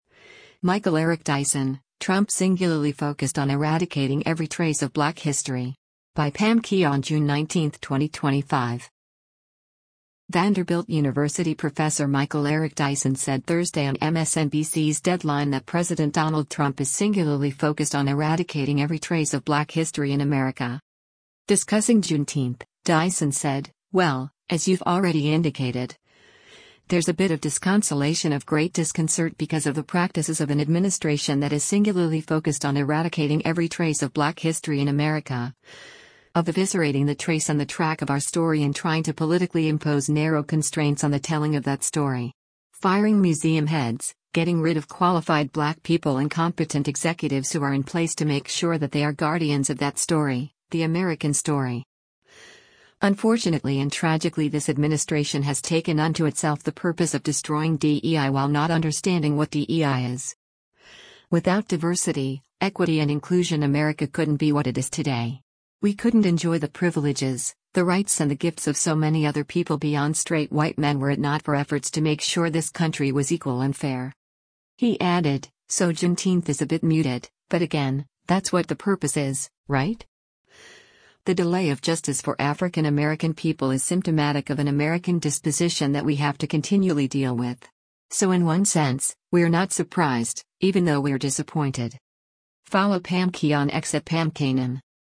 Vanderbilt University professor Michael Eric Dyson said Thursday on MSNBC’s “Deadline” that President Donald Trump “is singularly focused on eradicating every trace of black history in America.”